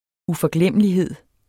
Udtale [ ufʌˈglεmˀəliˌheðˀ ]